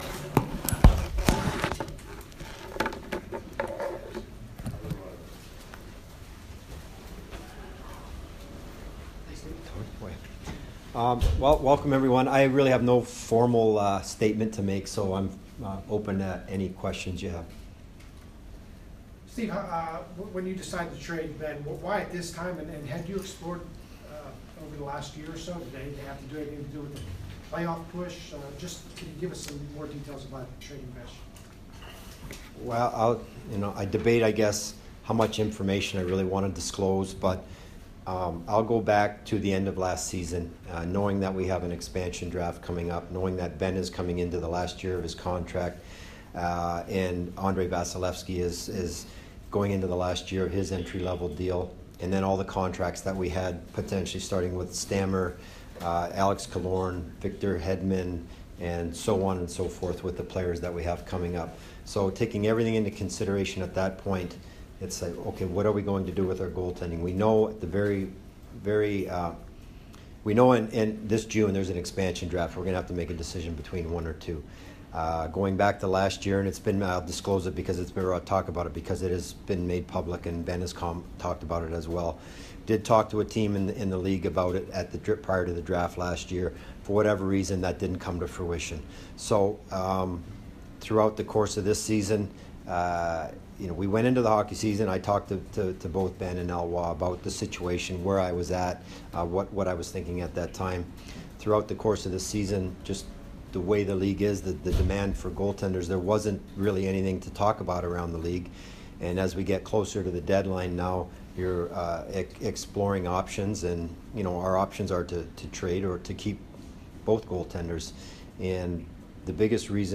Steve Yzerman Press Conference 2 - 27